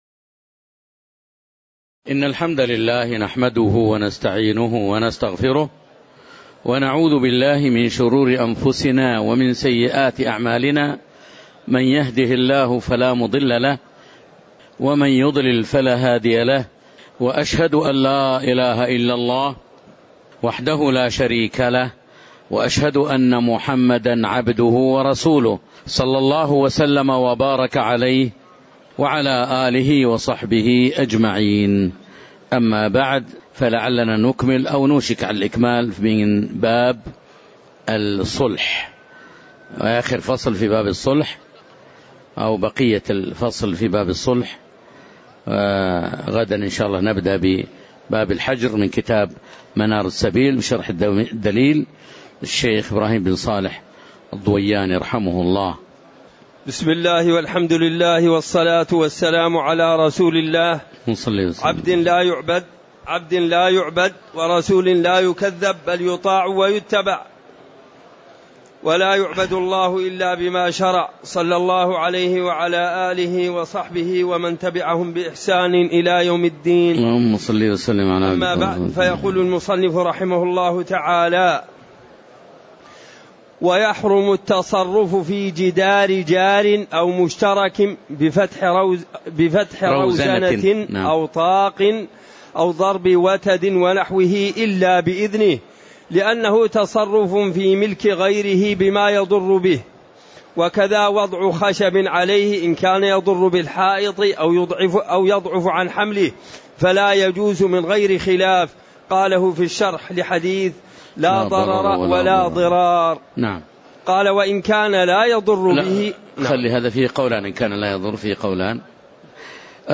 تاريخ النشر ٤ صفر ١٤٤١ هـ المكان: المسجد النبوي الشيخ